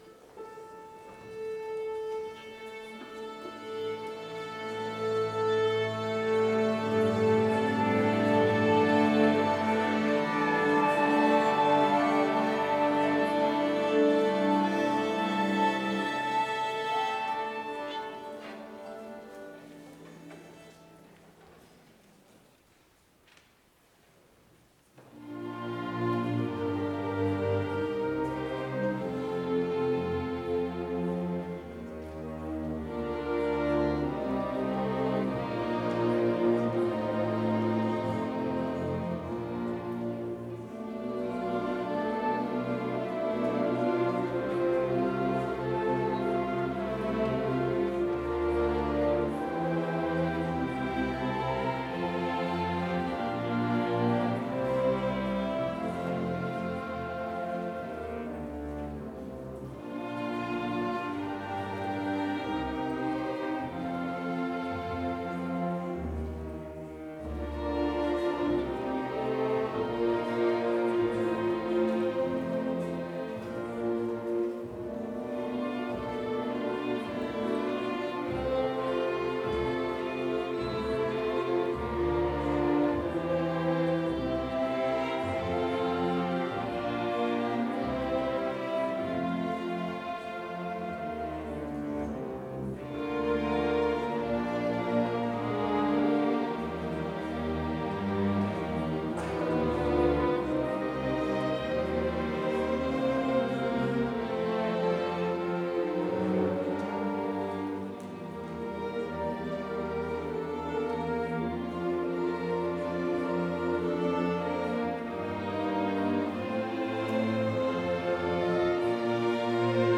Complete service audio for Chapel - Friday, January 24, 2025